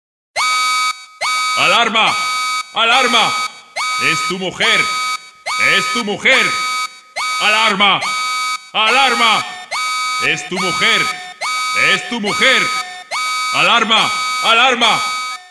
Categoría Alarmas